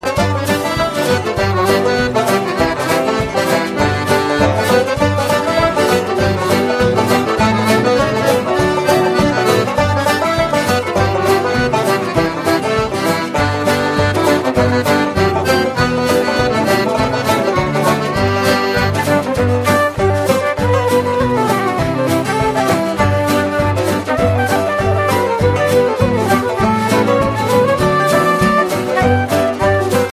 (breakdown)
a fun rag-style tune.